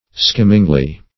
skimmingly - definition of skimmingly - synonyms, pronunciation, spelling from Free Dictionary Search Result for " skimmingly" : The Collaborative International Dictionary of English v.0.48: Skimmingly \Skim"ming*ly\, adv.